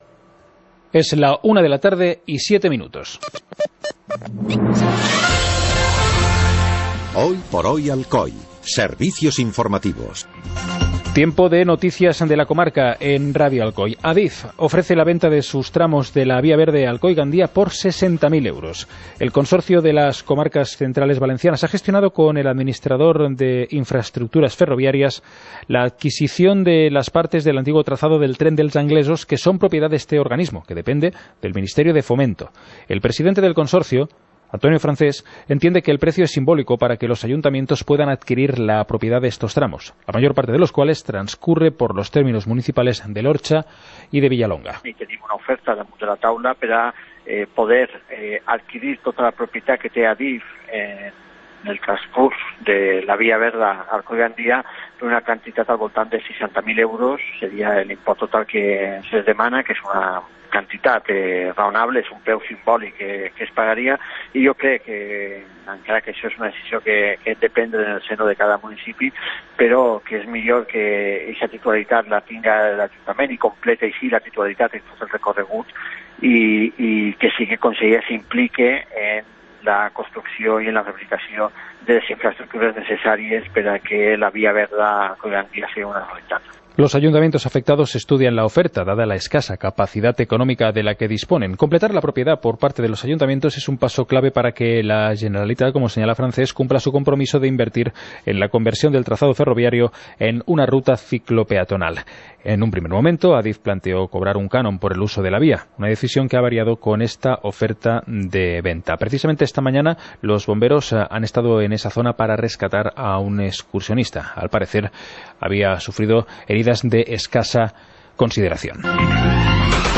Informativo comarcal - jueves, 04 de enero de 2018